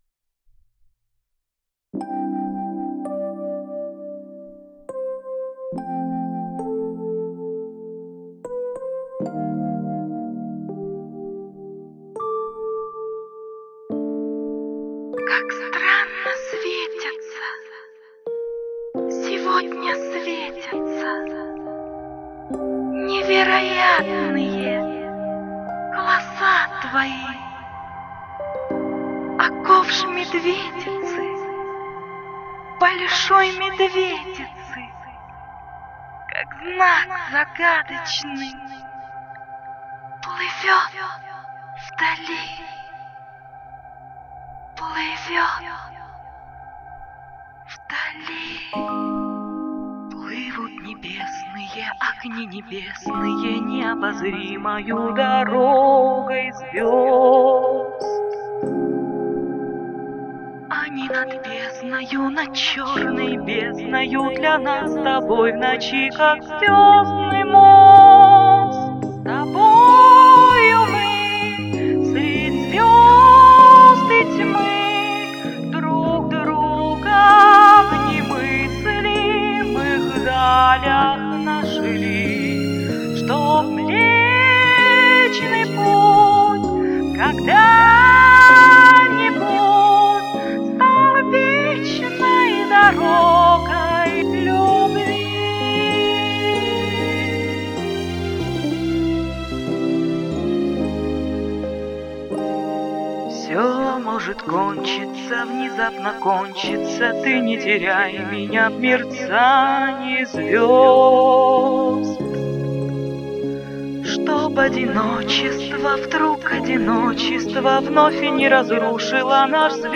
Песня романтичная и нежная!